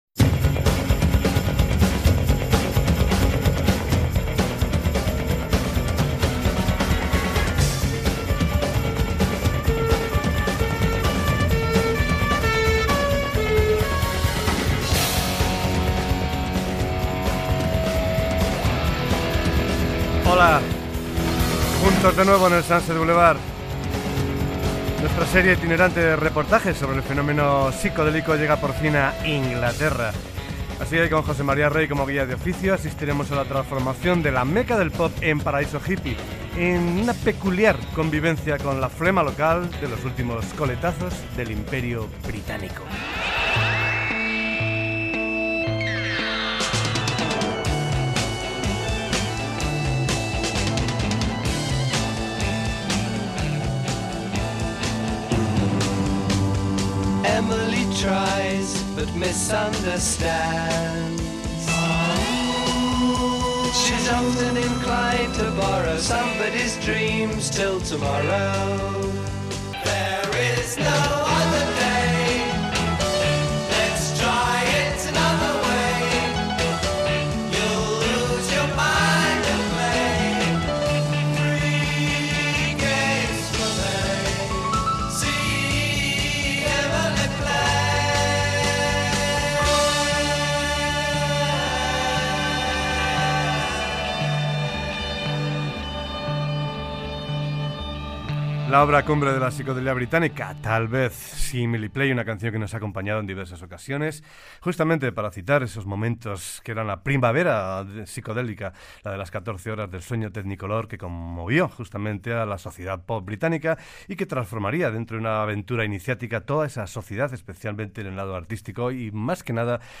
Sintonia, presentació del programa dedicat a la música psicodèlica britànica de l'any 1967, tema musical, comentari i tema musical
Musical
FM